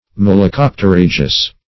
malacopterygious - definition of malacopterygious - synonyms, pronunciation, spelling from Free Dictionary
Search Result for " malacopterygious" : The Collaborative International Dictionary of English v.0.48: Malacopterygious \Mal`a*cop`ter*yg"i*ous\, a. (Zool.)